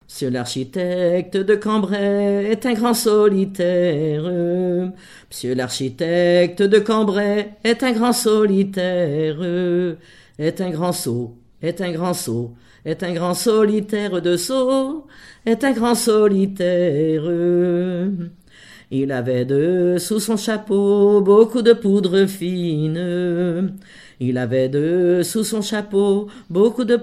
Genre énumérative
collectif de chanteuses de chansons traditionnelles
Pièce musicale inédite